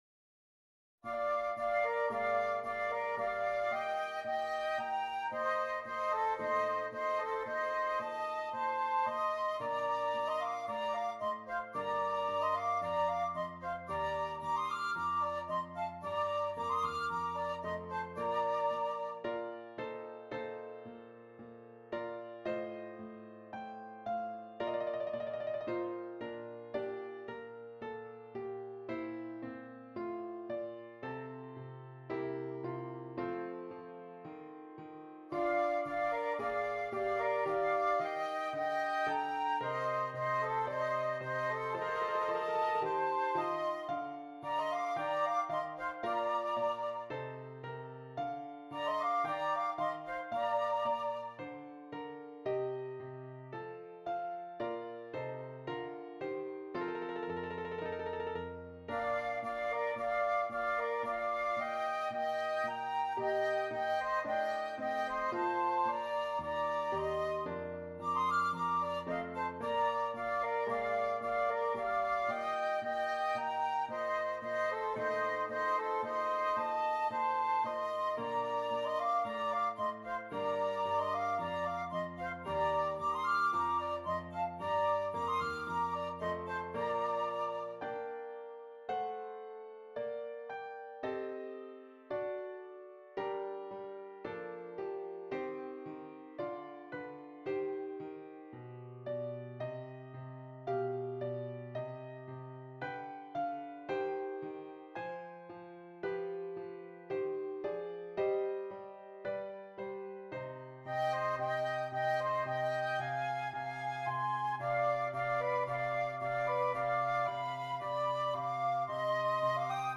2 Flutes and Keyboard